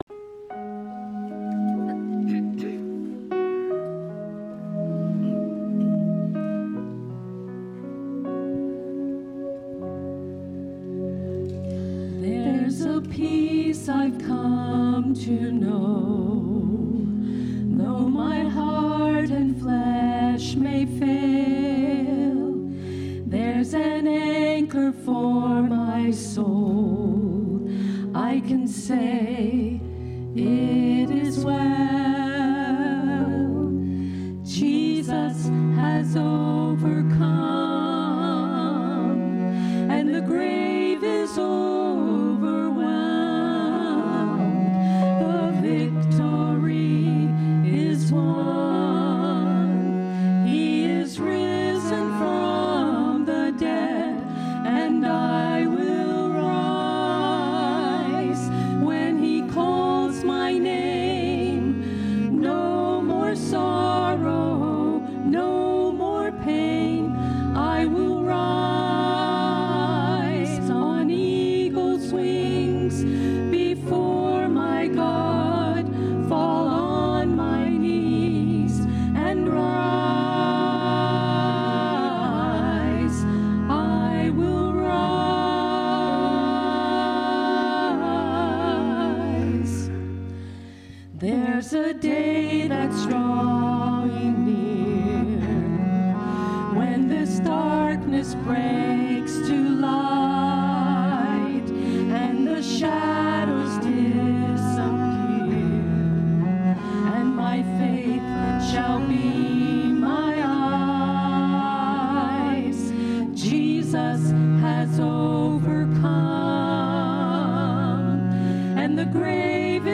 cello
Keyboard